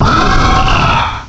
Add all new cries
cry_not_honchkrow.aif